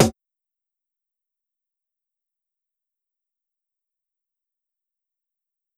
Snare (Get It Together).wav